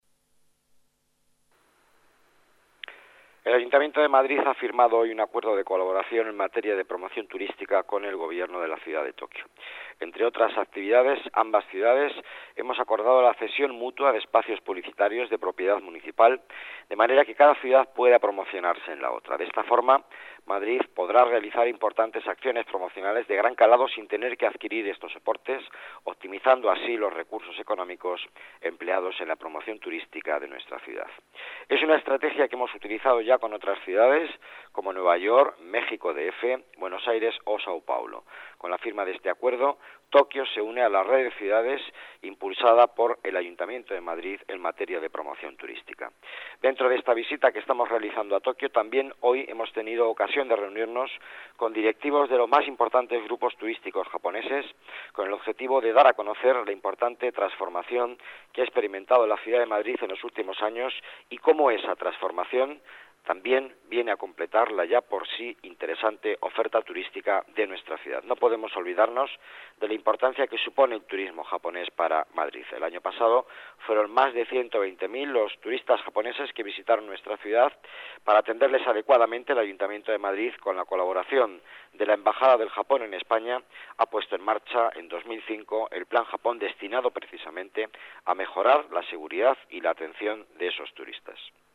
Nueva ventana:Declaraciones del delegado de Economía, Empleo y Participación Ciudadana, Miguel Ángel Villanueva.